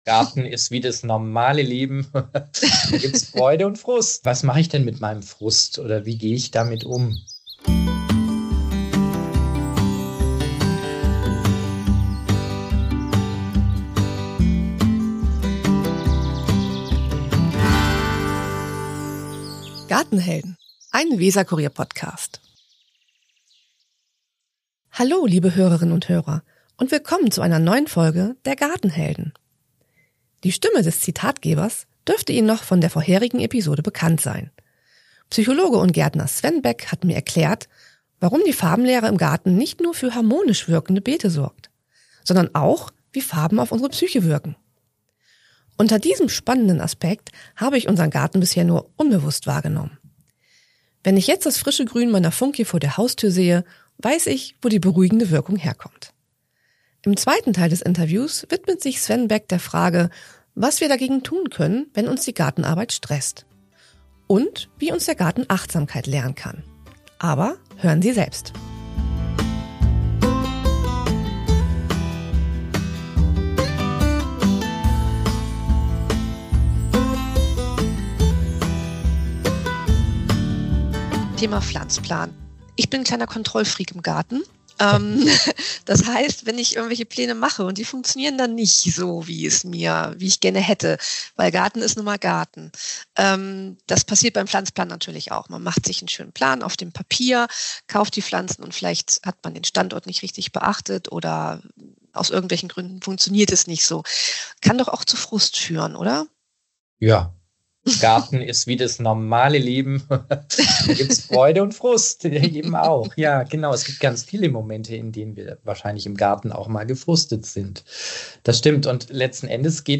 Im zweiten Teil des Interviews erklärt er, was Ihr dagegen tun könnt, wenn Euch die Gartenarbeit stresst und wie Ihr im Garten achtsamer werdet.